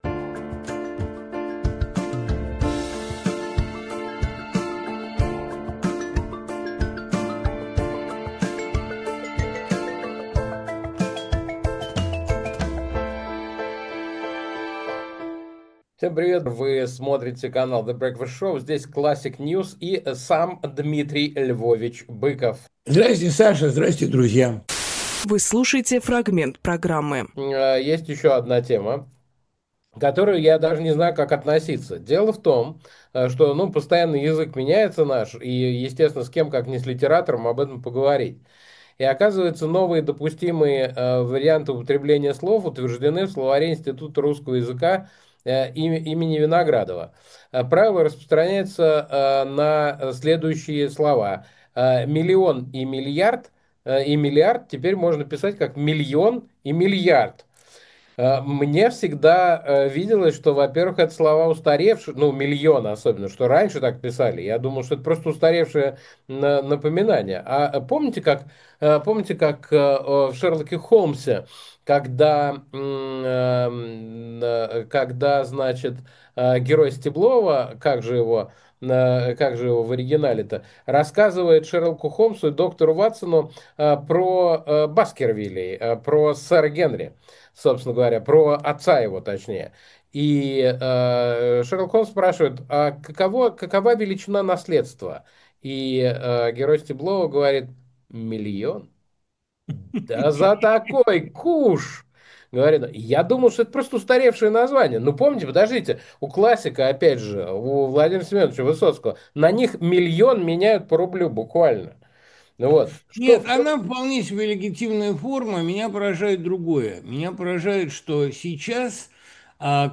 Дмитрий Быковпоэт, писатель, журналист
Александр Плющевжурналист
Фрагмент эфира от 18.08.25